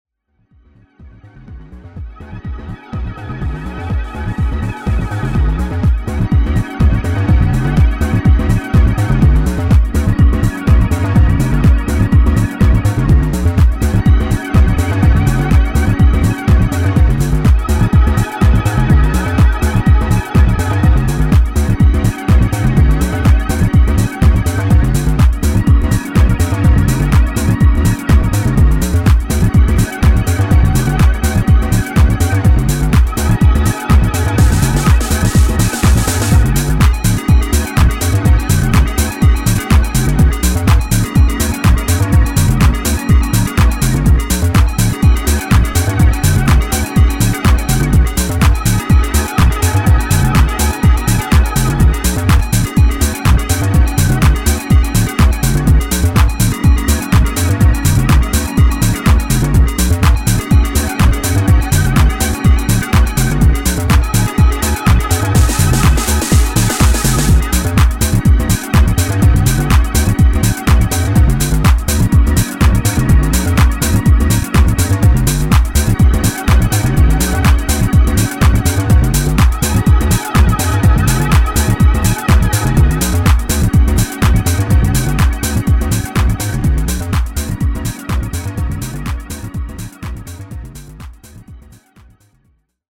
デトロイト・テクノ的郷愁が伝わるパッドが心地良いアフターアワーズグルーヴァー
UKテック・ハウスの真髄ここにあり。